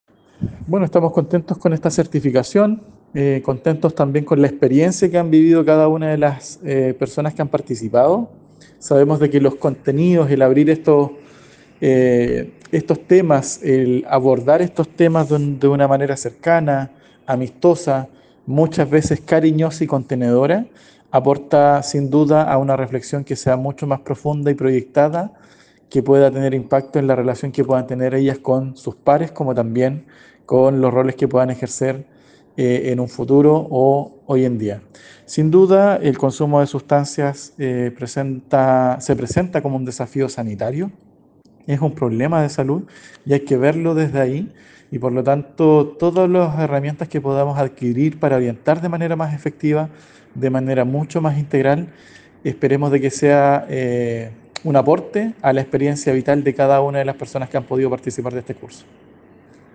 Escucha a Carlos Colihuechún, director regional de Senda, en este enlace: